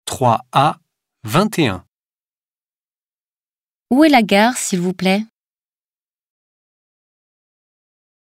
Folgende Hördialoge stehen zur Verfügung: